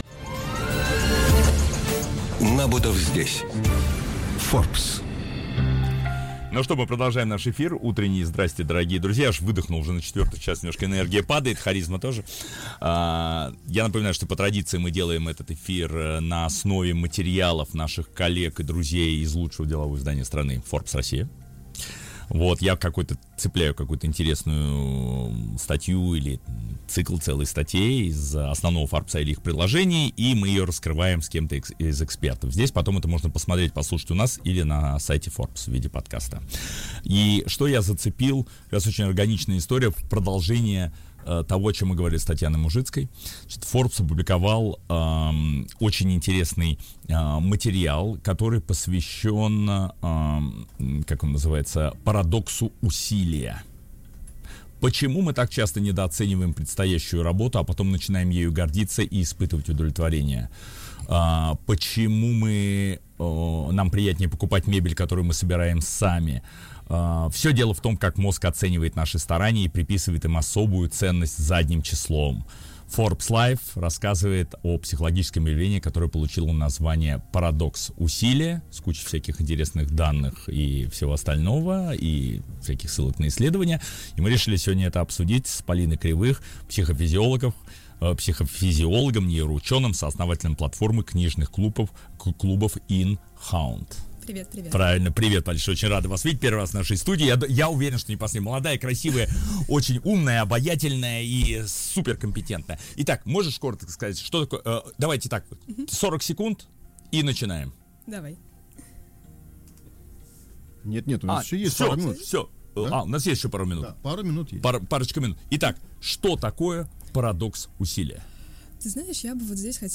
Каждый четверг в утреннем эфире радиостанции «Серебряный дождь» — программа «Набутов здесь. Forbes».